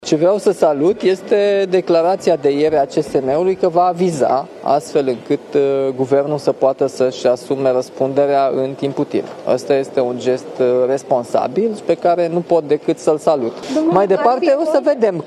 Reacție de la președintele Românei Nicușor Dan privind reforma pensiilor magistraților. Anunțul Consiliului Superior al Magistraturii privind emiterea unui aviz este un gest de responsabilitate, spune Nicușor Dan.
Șeful statului a fost întrebat ce părere are despre decizia coaliţiei de guvernare în ce priveşte pensiile magistraţilor, pensie de 70% din ultimul salariu net şi 15 ani perioada de tranziţie.